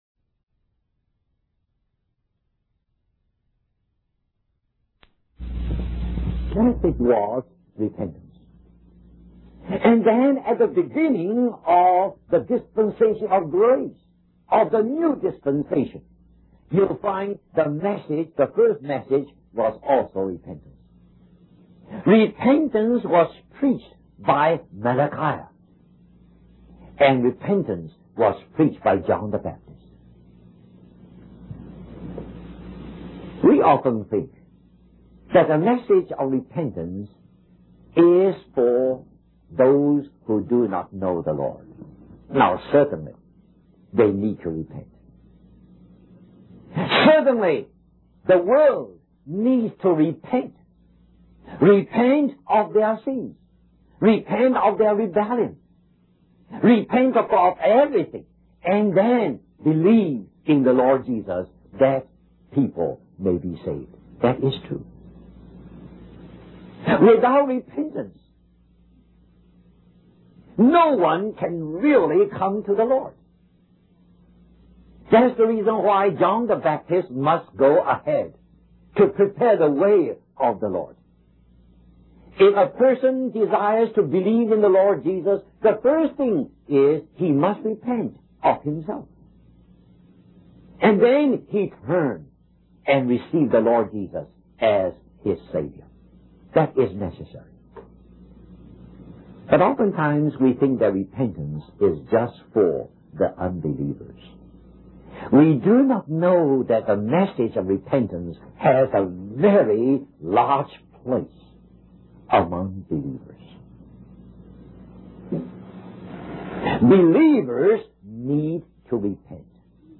US Stream or download mp3 Summary The beginning of the message was missing from the original tape.